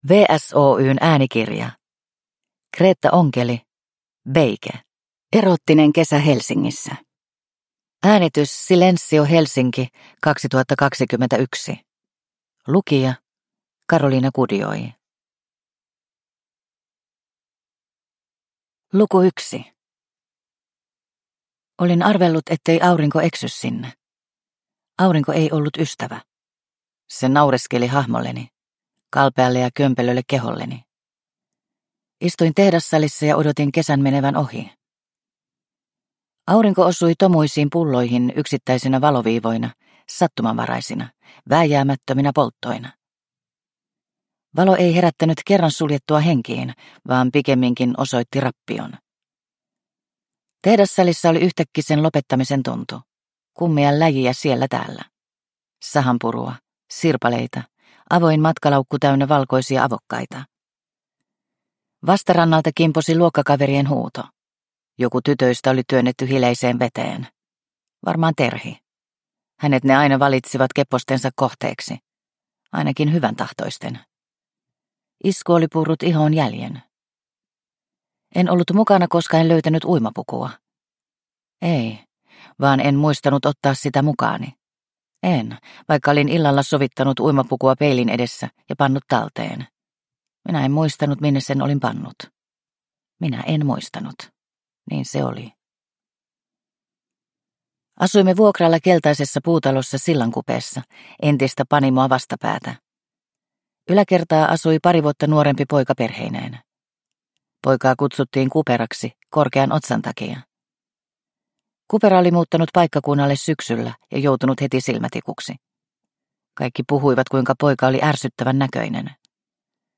Beige – Ljudbok – Laddas ner